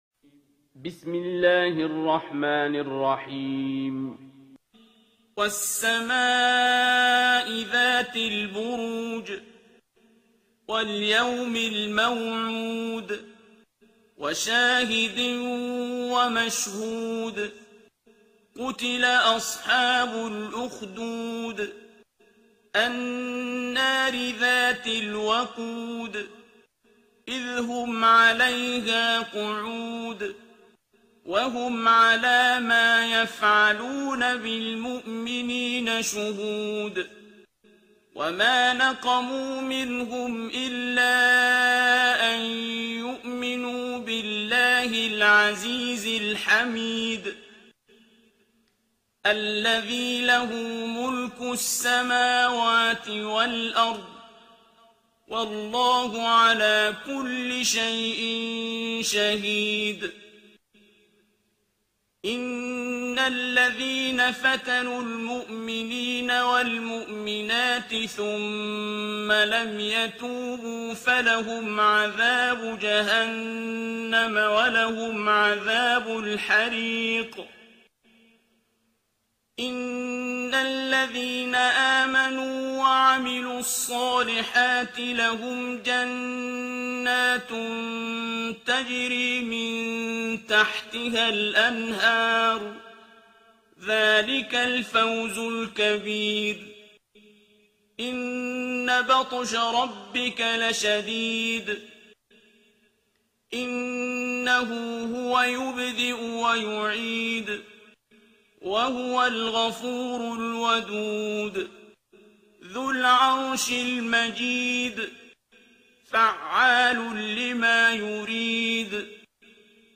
ترتیل سوره بروج با صدای عبدالباسط عبدالصمد
085-Abdul-Basit-Surah-Al-Burooj.mp3